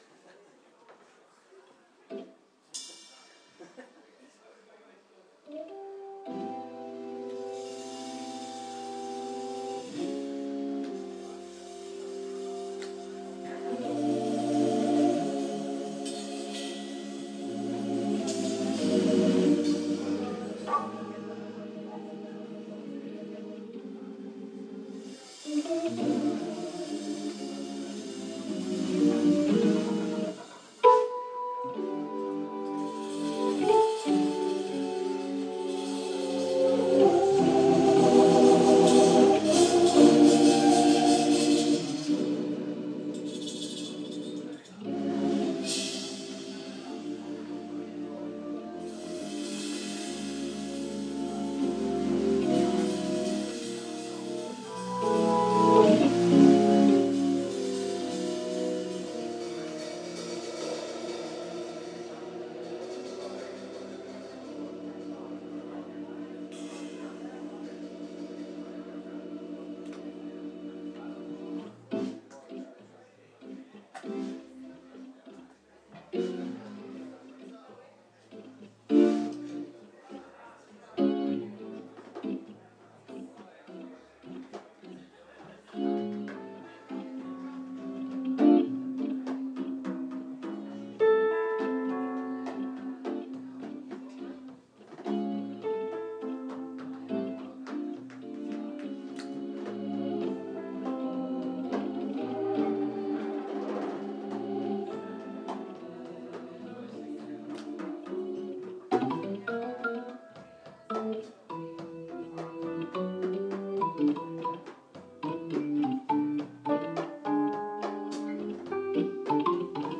Un concerto imperdibile per tutti gli amanti dell’Hammond.
alla batteria
alla chitarra